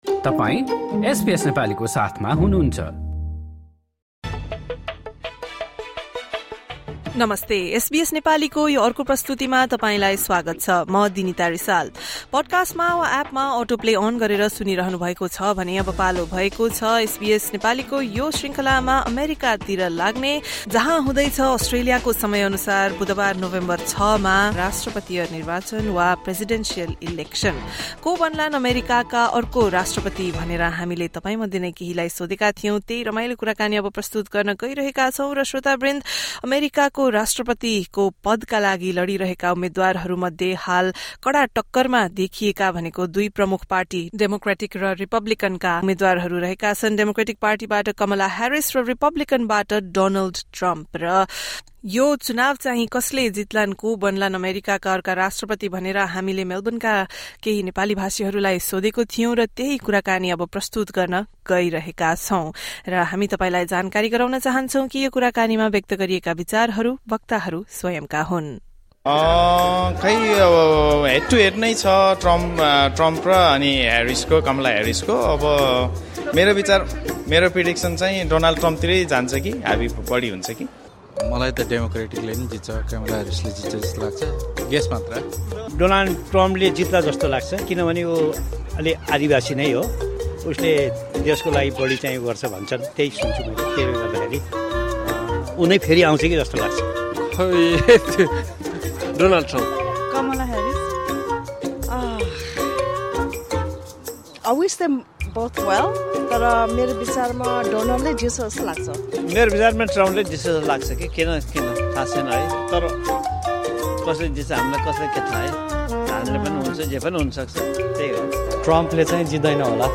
Melbourne's Nepali community members shared their thoughts with SBS Nepali.